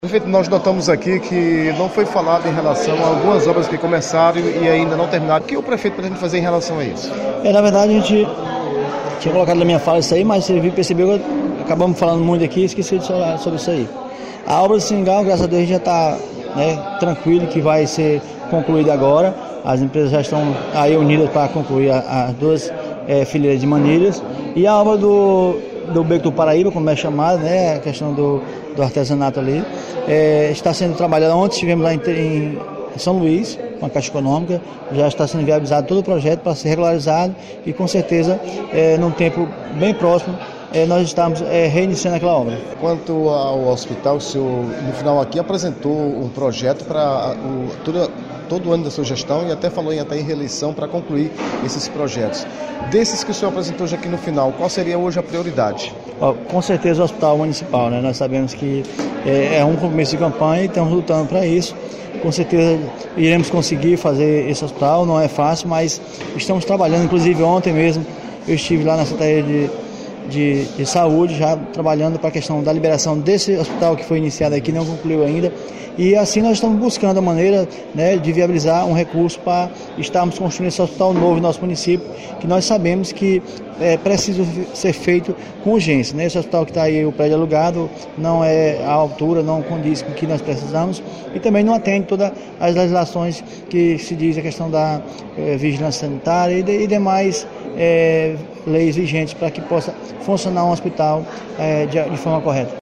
Em nenhum momento foi relatado pelos responsáveis pelas pastas, a situação das obras que começaram e não tiveram sequências (calçadão e bosque Seringal),  e nada sobre o hospital municipal de Pedreiras, que atualmente funciona em um prédio alugado, mas, para não deixar ninguém desinformado sobre essas duas situações, o Blog  ouviu o prefeito de Pedreiras, Antônio França.